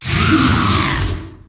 Grito de Sandygast.ogg
Grito_de_Sandygast.ogg